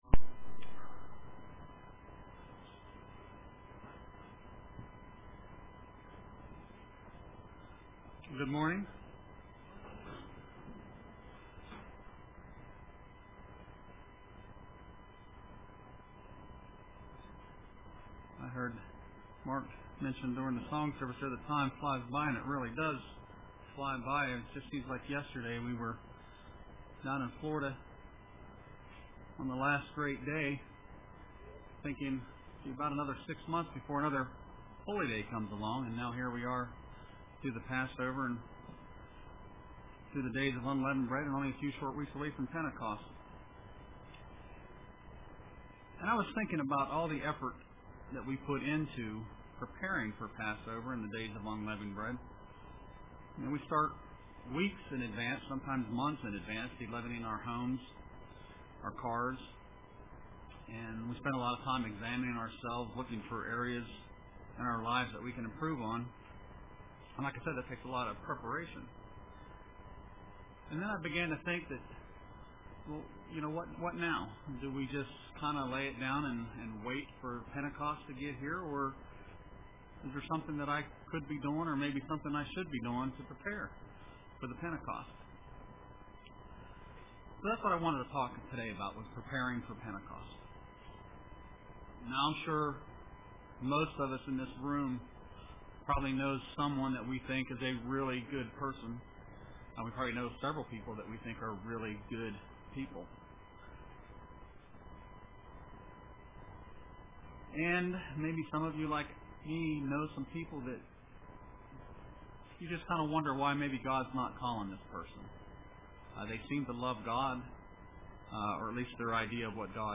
Print Preparing for Pentecost UCG Sermon Studying the bible?